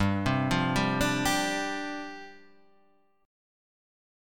G7sus4 chord {3 3 0 0 1 1} chord